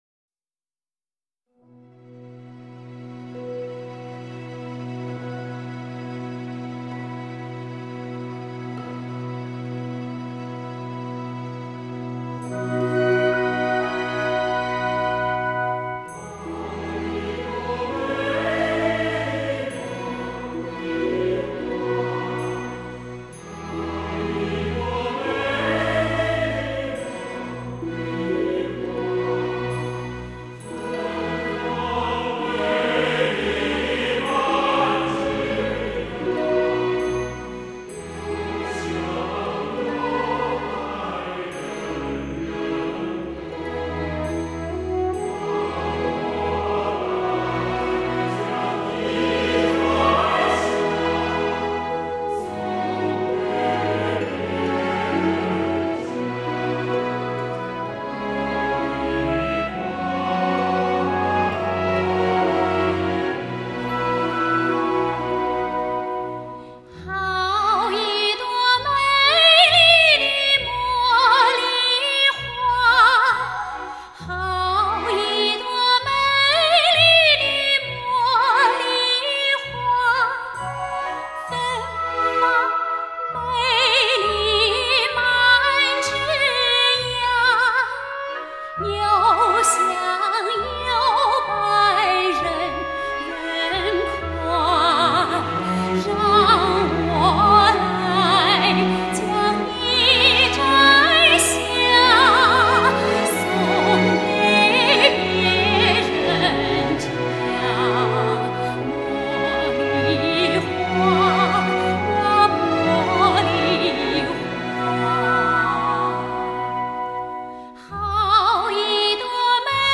20年歌唱艺术巅峰之作，[历年金曲]精选，顶级录音制作，聆听至尊极品！
她音色清纯甜美，音域舒展流畅，演唱细腻自然。